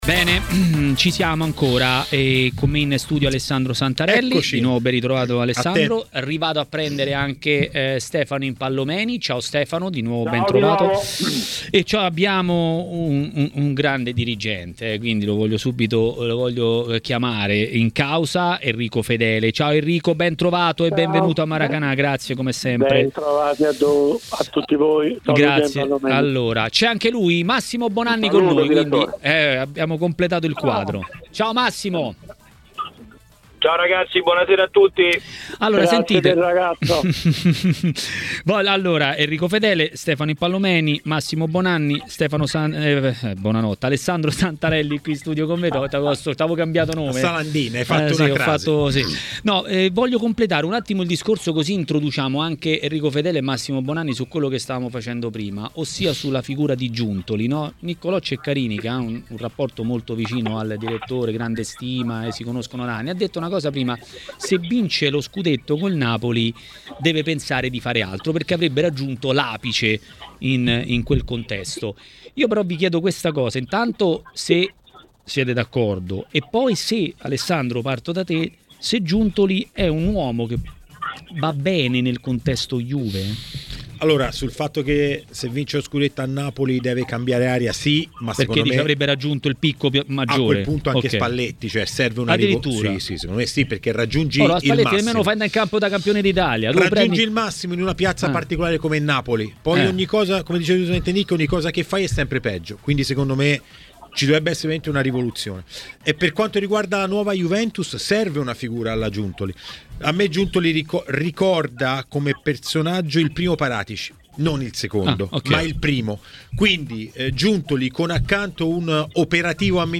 trasmissione del pomeriggio di TMW Radio